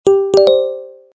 Рингтоны на смс и уведомления